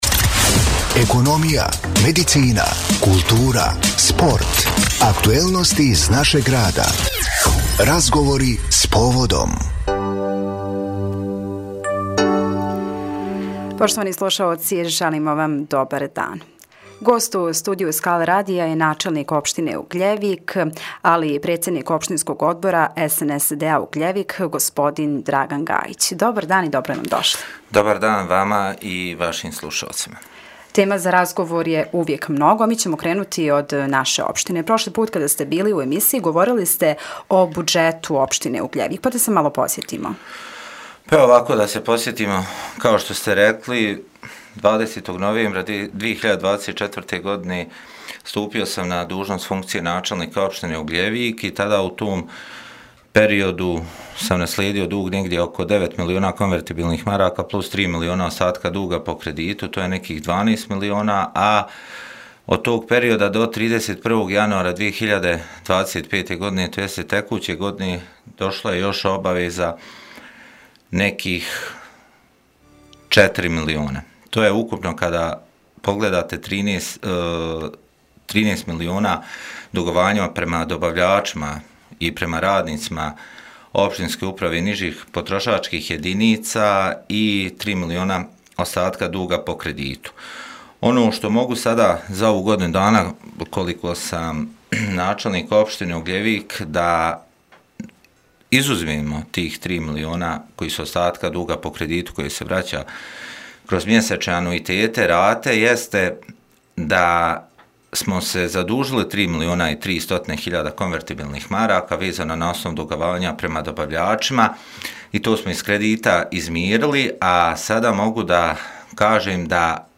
Gost u studiju Skala radija bio je načelnik Ugljevika i predsjednik Opštinskog odbora SNSD-a, Dragan Gajić.